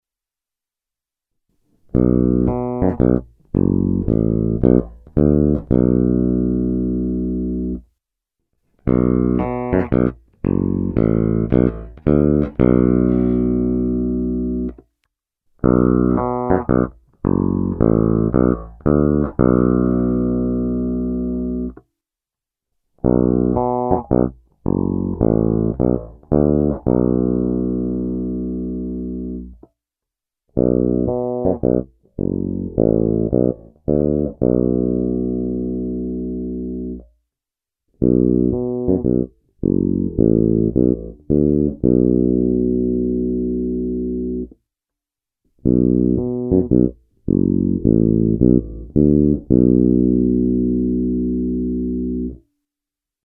Oba snímače